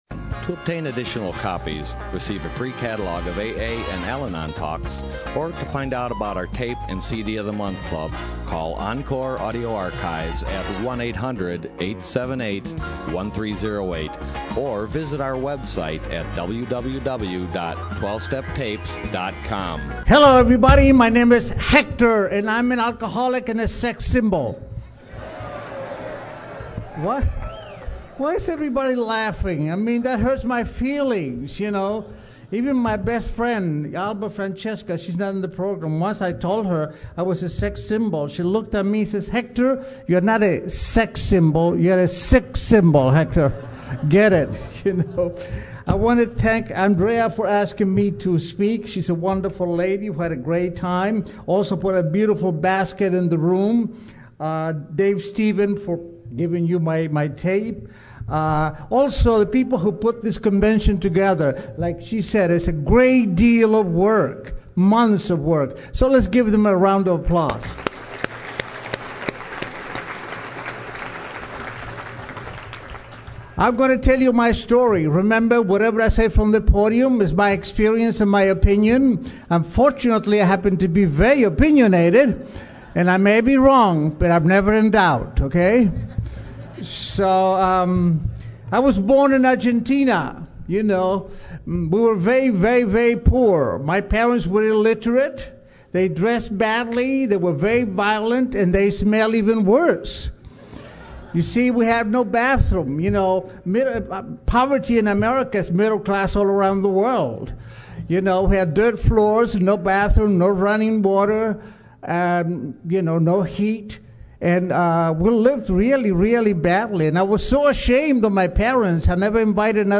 Orange County AA Convention 2014